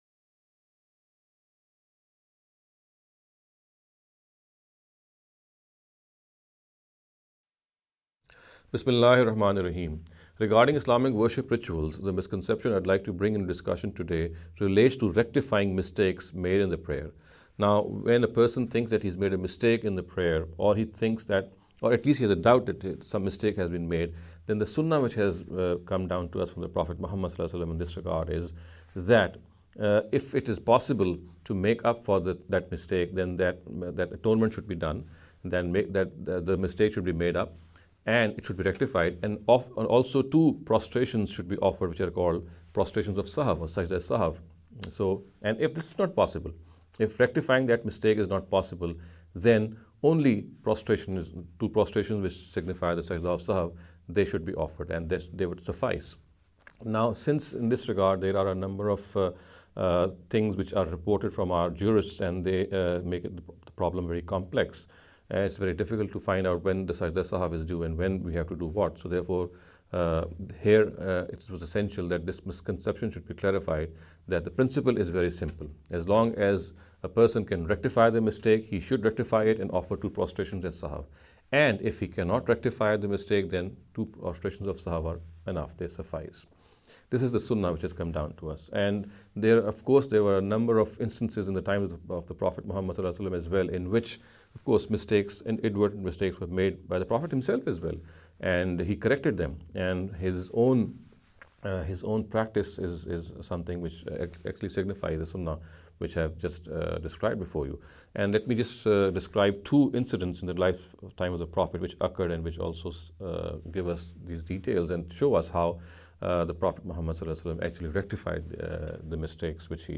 This lecture series will deal with some misconception regarding Islamic worship rituals.